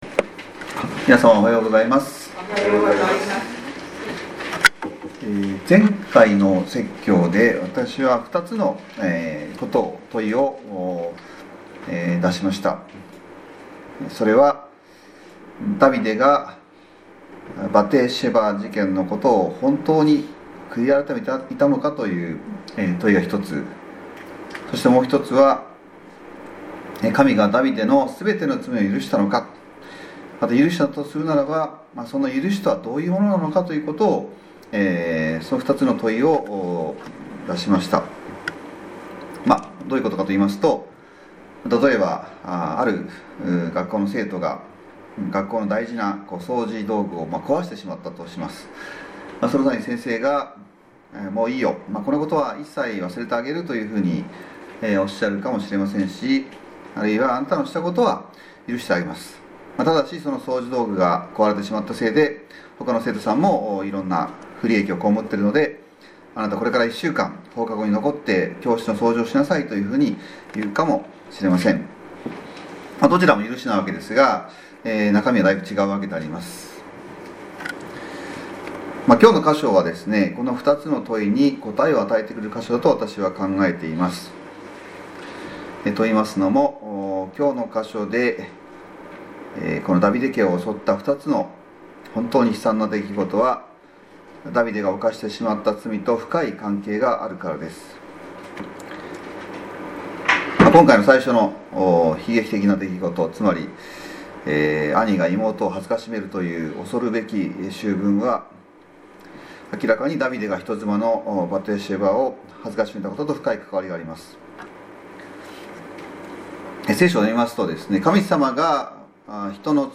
せっかくの主日礼拝なので、心が高揚する話や、心が温まる話を聞きたいと思われるでしょうし、私もできればそうしたいのですが、しかし講解説教ですので、この箇所は飛ばしてとか、そういうことはできません。